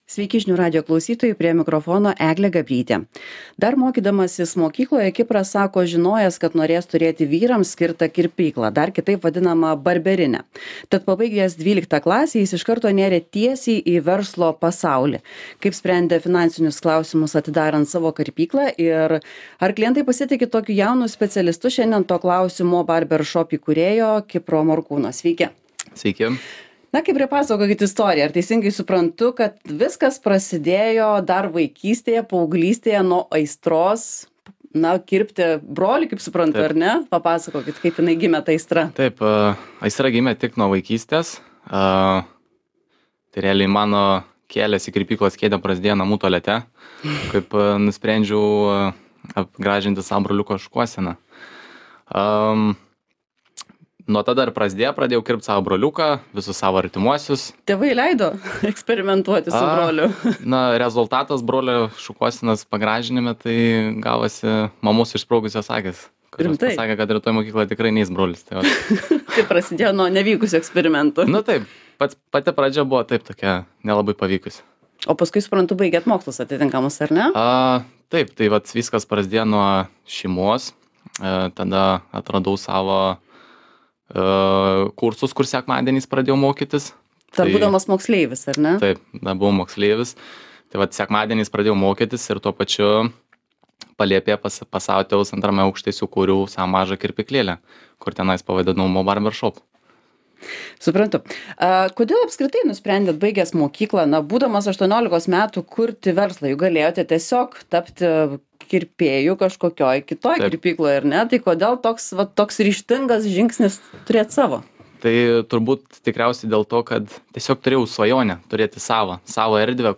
Apie tai – pokalbis